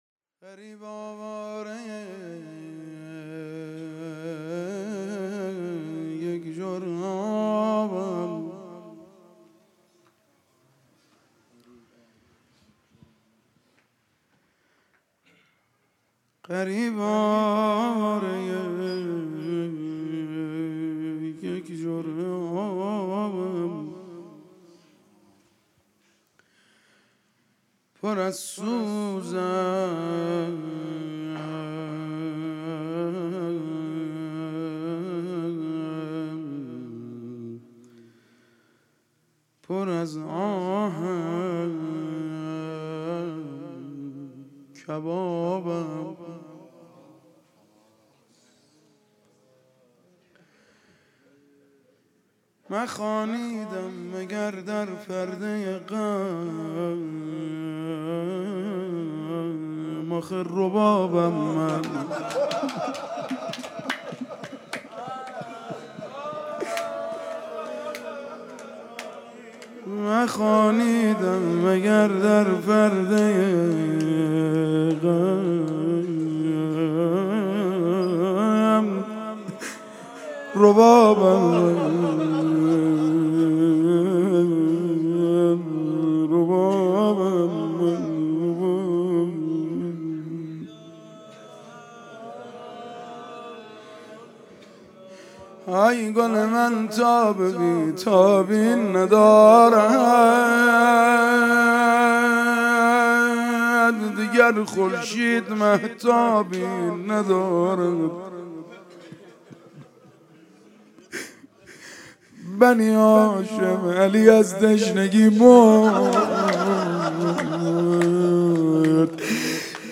روضه پایانی